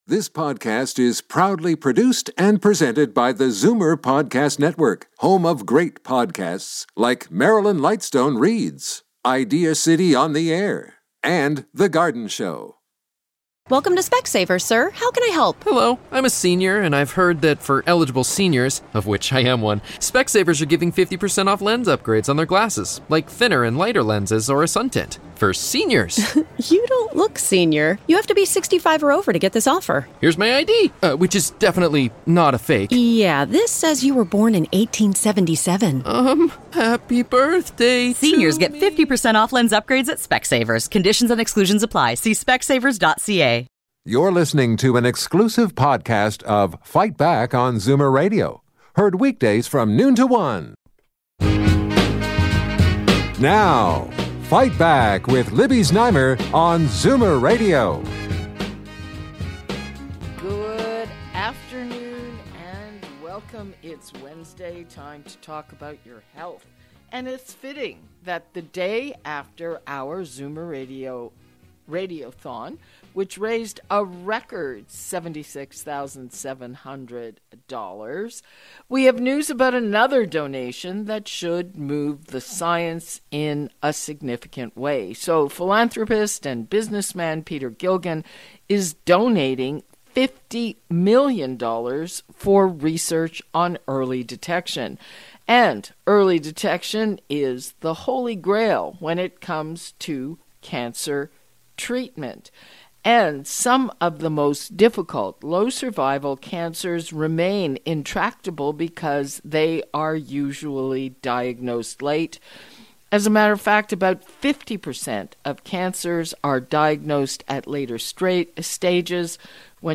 … continue reading 501 episodes # Politics # News Talk # News # Libby Znaimer # Zoomer Podcast Network # Medical Record